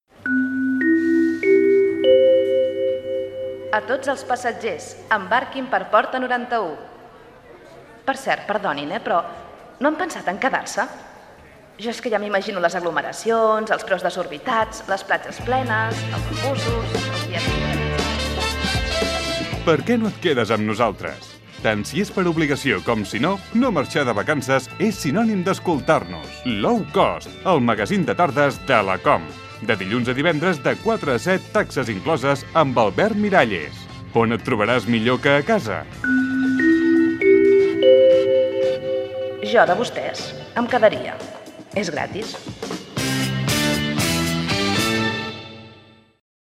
Promoció del programa